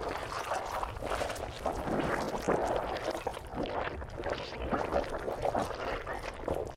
TentacleIdle.ogg